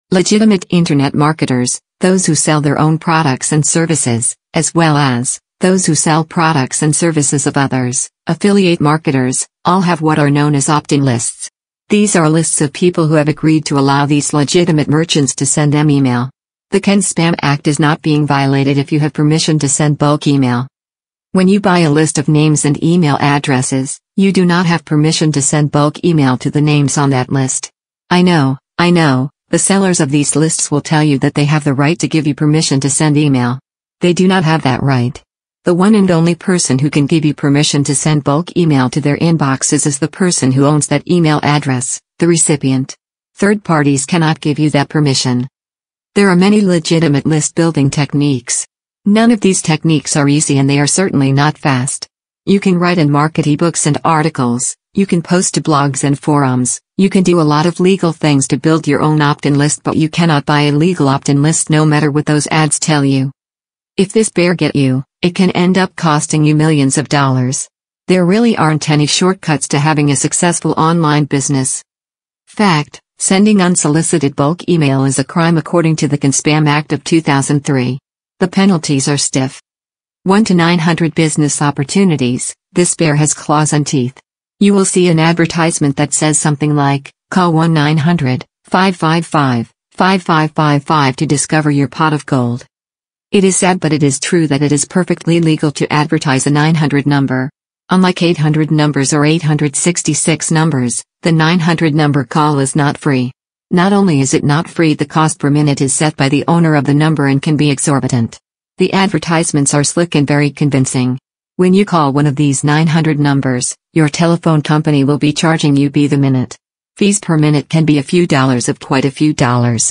Each episode features insightful conversations with entrepreneurs, freelancers, and experts who share their personal stories, practical strategies, and lessons learned from building successful remote careers.